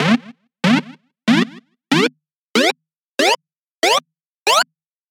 Game jump Sound
8bit boink gaming hit jump jumping mario super sound effect free sound royalty free Gaming